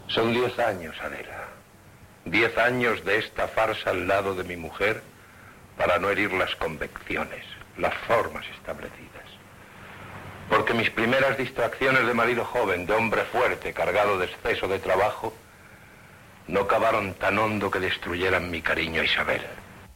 Fragment del serial. Monòleg de Perico.
Ficció